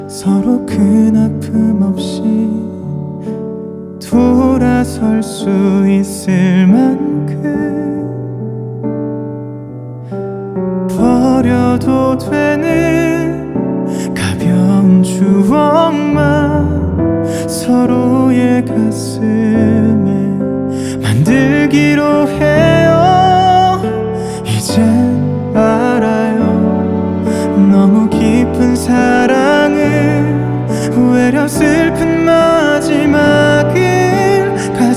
Жанр: Поп музыка
K-Pop